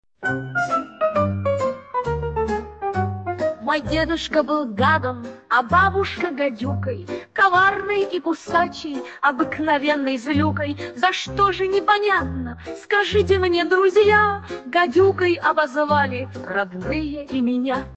Авторская песня для детей
Фрагмент 2-го варианта исполнения: